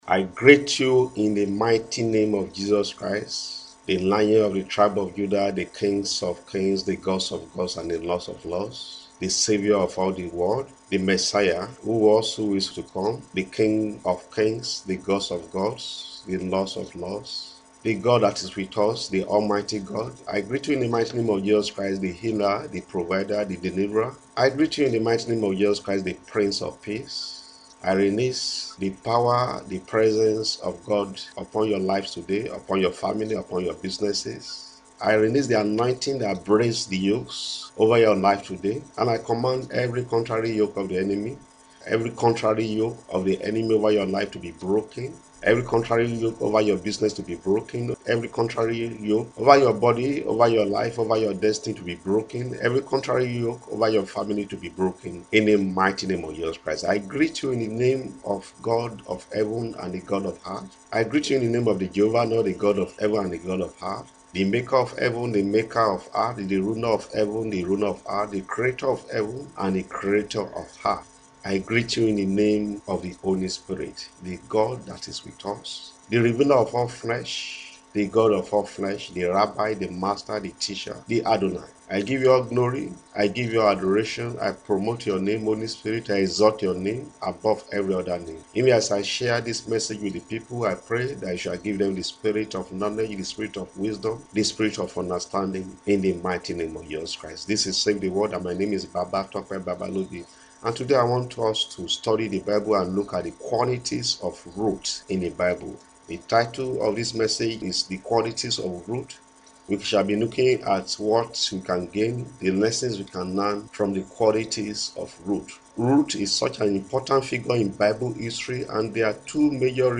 Audio sermons: lessons from Bible qualities of Ruth - Save the World Ministry